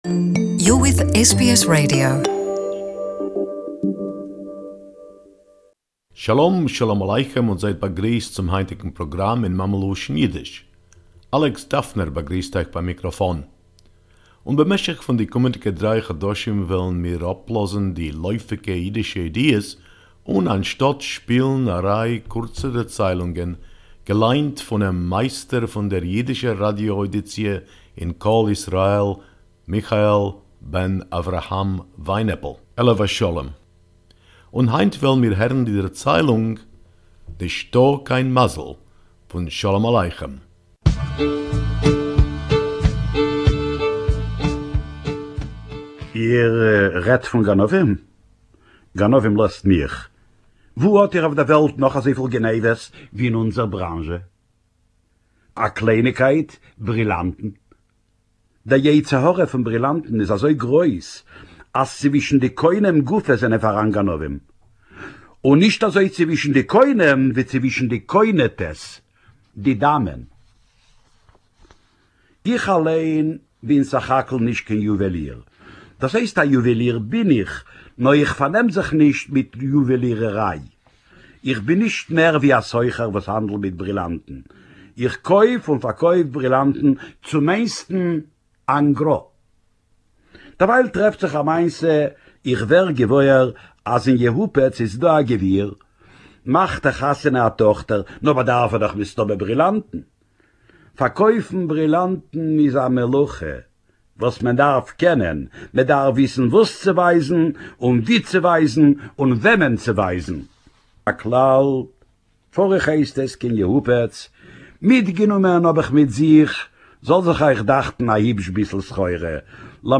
Yiddish Story, “There’s No Luck”